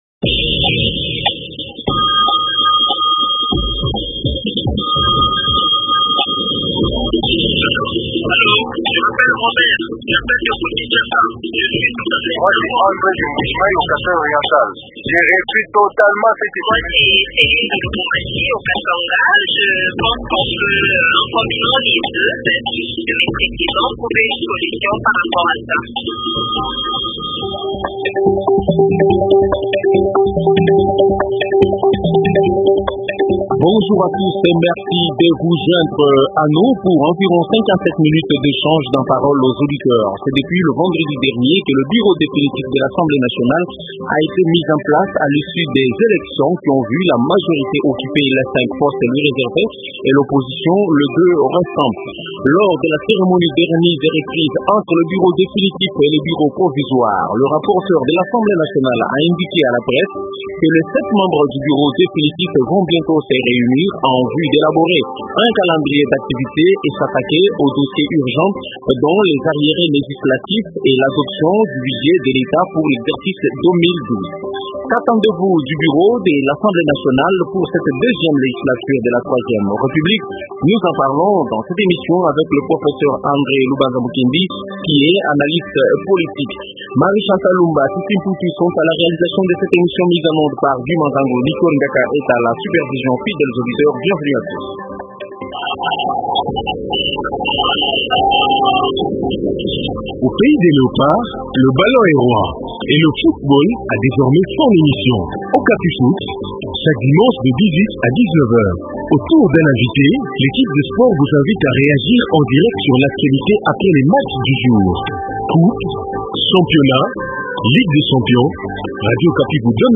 analyste politique.